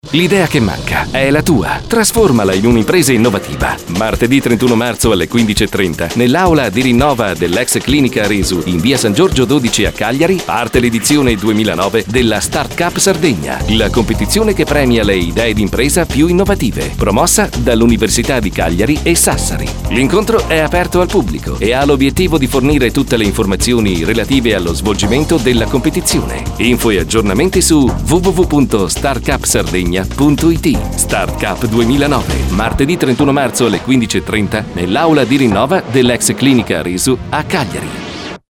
spot radiolina  spot radiopress